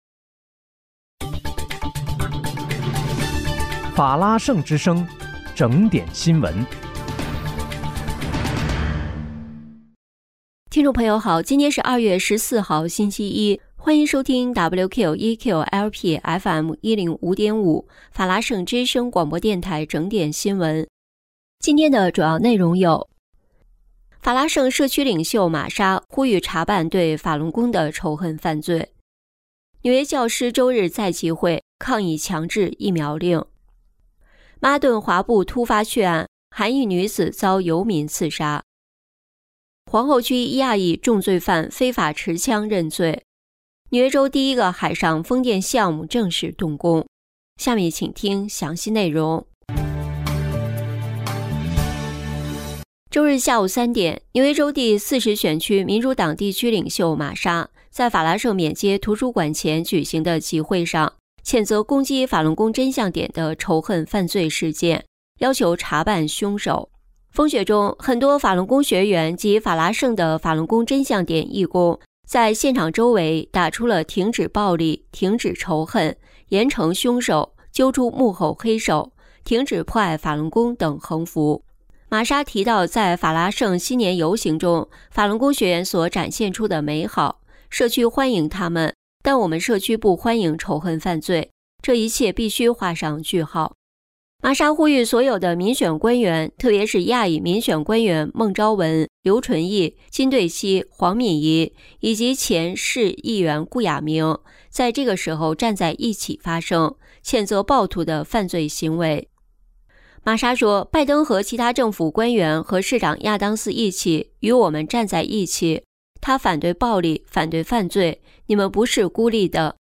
2月14日（星期一）纽约整点新闻